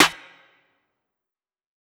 uzi snare 2.wav